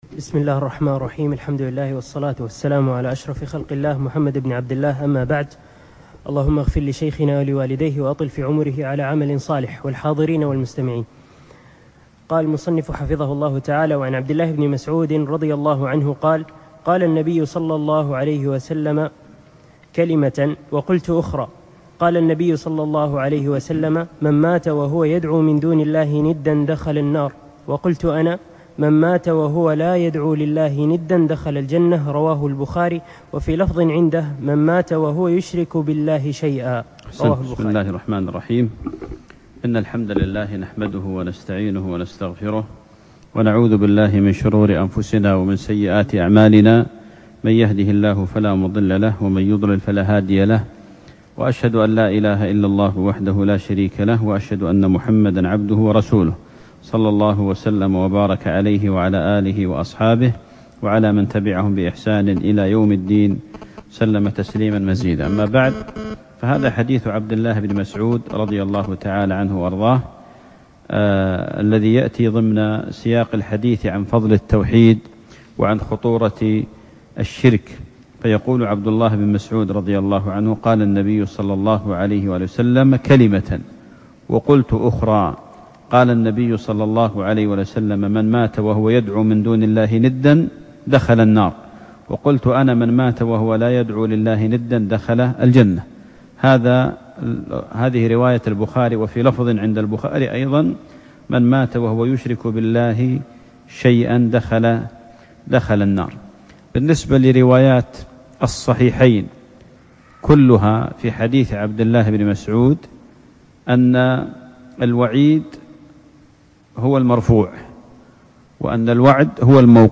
الدرس الثامن